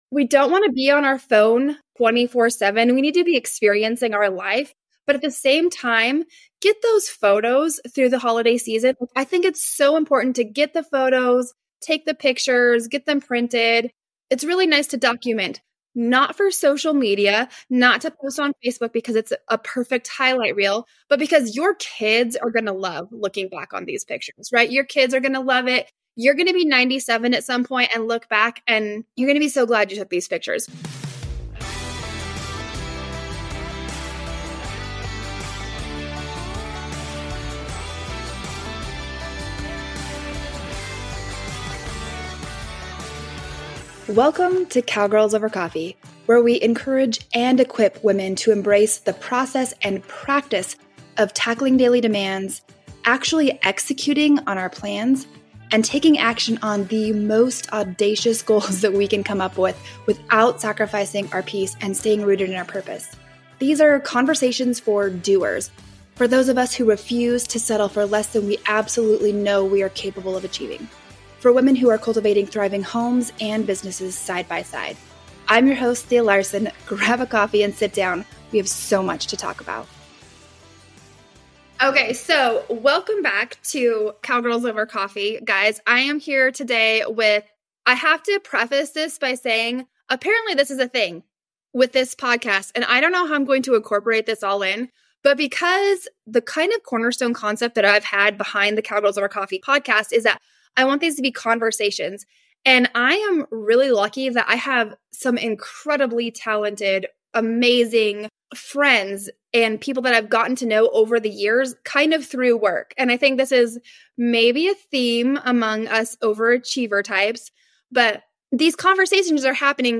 These professional photographers are with me today to share their tips on capturing authentic moments, creating a relaxed atmosphere, and using editing apps to enhance photos. They also provide advice on outdoor and indoor photography, taking photos in a barn setting, and the importance of printing photos.